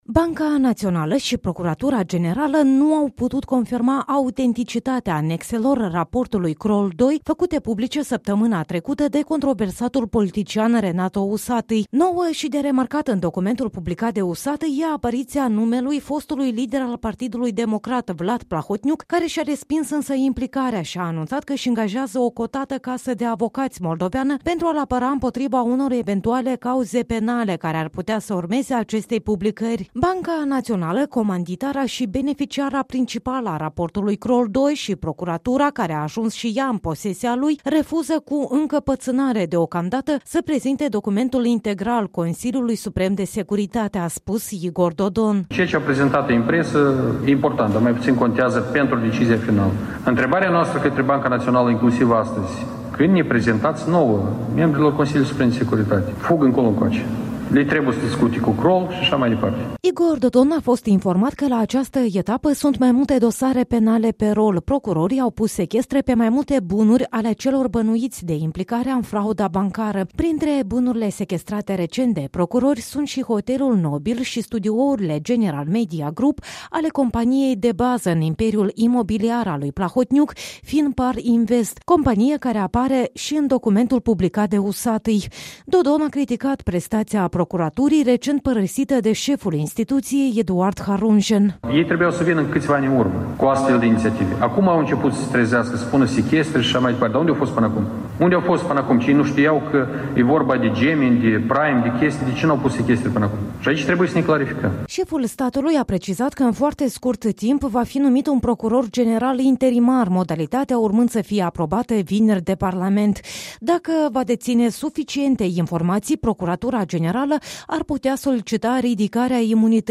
Președintele Igor Dodon a ținut astăzi un briefing de presă după ședința Consiliului Suprem de Securitate, pe care tot el o convocase.